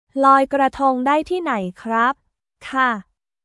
ロイ・クラトン ダイ ティーナイ クラップ/カー